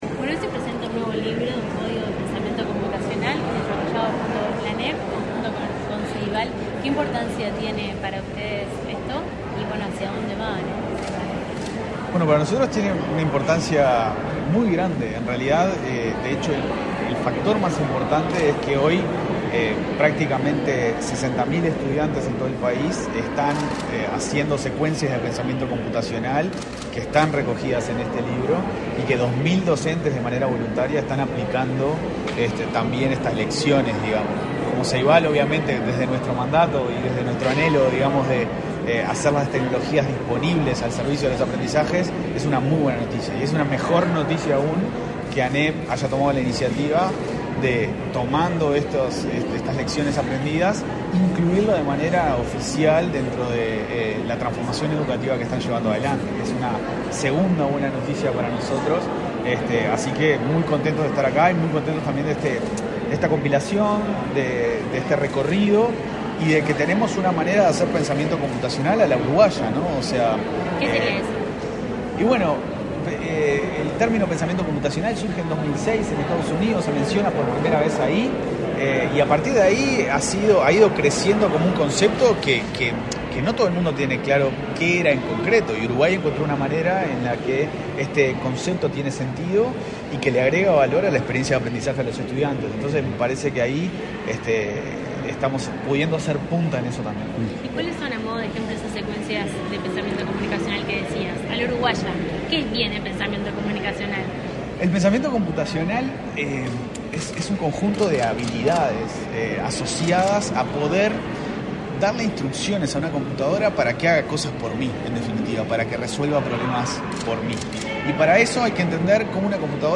Declaraciones a la prensa del presidente de Ceibal, Leandro Folgar
Declaraciones a la prensa del presidente de Ceibal, Leandro Folgar 08/07/2022 Compartir Facebook X Copiar enlace WhatsApp LinkedIn La Administración Nacional de Educación Pública (ANEP) y Ceibal presentaron un libro sobre pensamiento computacional, este 8 de julio. Tras el evento, Leandro Folgar efectuó declaraciones a la prensa.